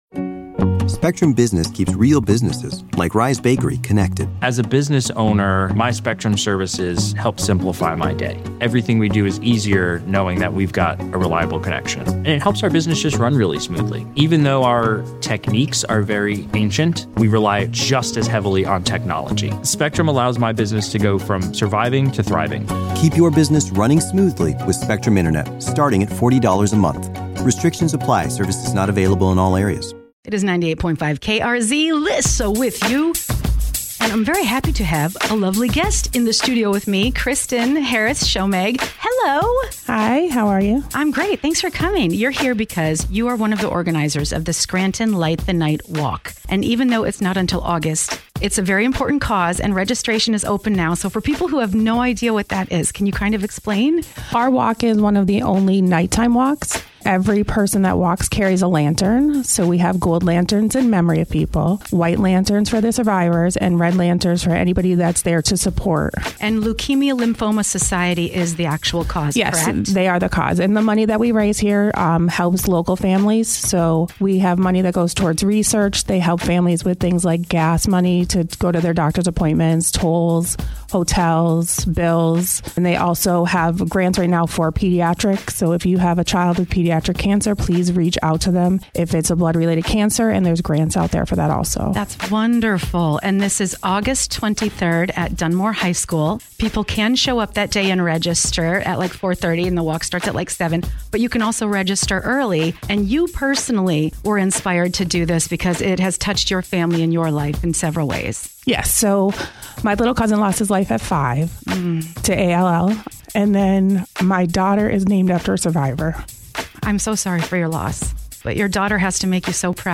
Scranton Light The Night in studio